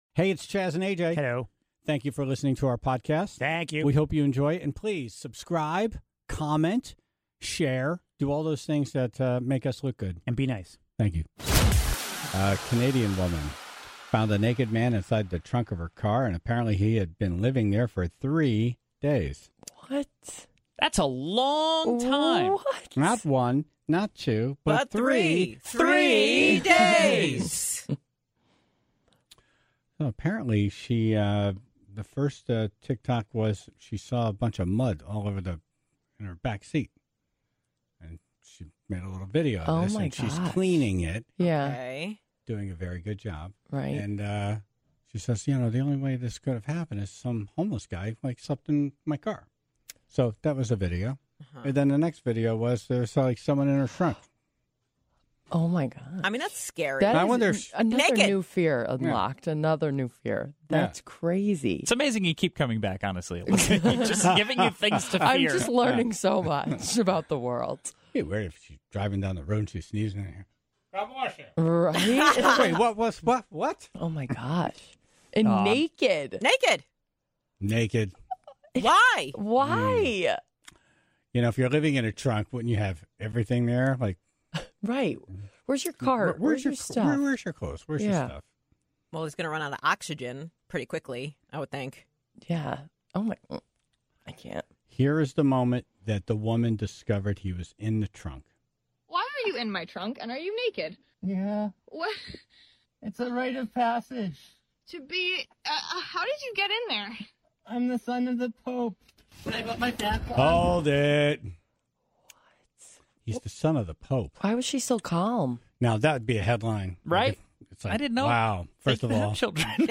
(0:00) A couple bought a used couch, only to find a lifetime of nail clippings in the cushions. The Tribe called in their horror stories with getting used items.
(22:08) Legendary drummer Carl Palmer talks about his show at the Ridgefield Playhouse and his thoughts on why bands like ELP are not in the Hall of Fame.